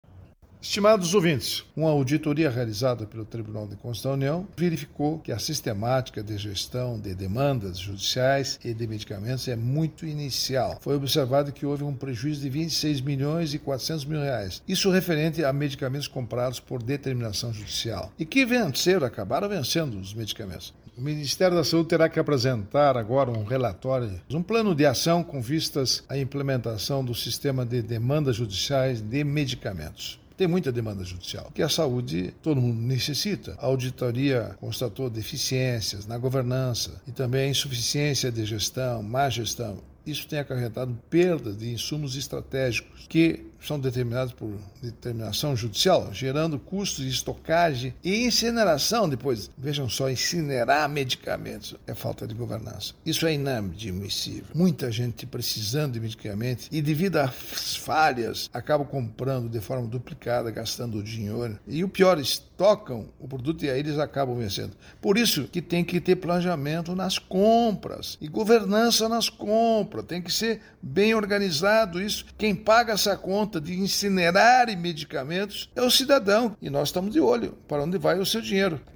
Comentário do ministro Augusto Nardes, TCU.